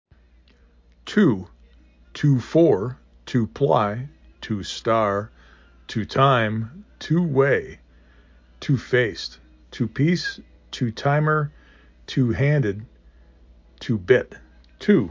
U = do, room
Local Voices
Italy